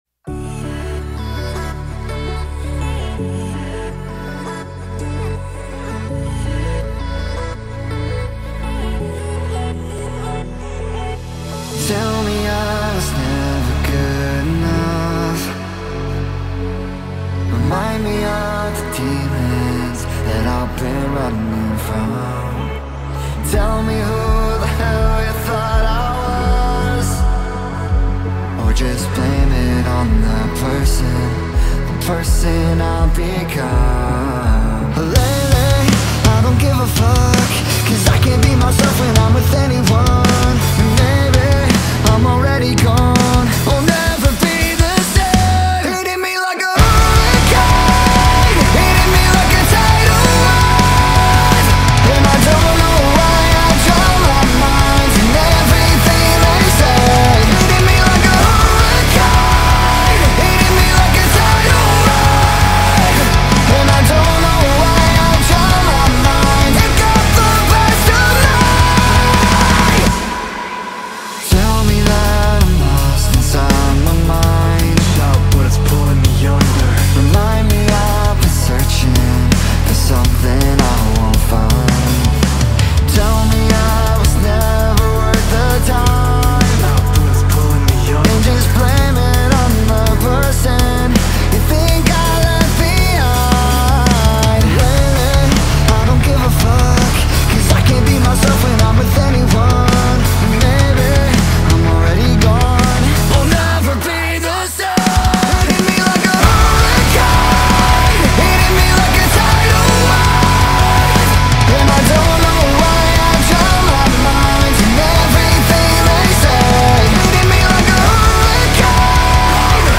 در سبک پاپ راک و آلترنتیو راک
یک راک بند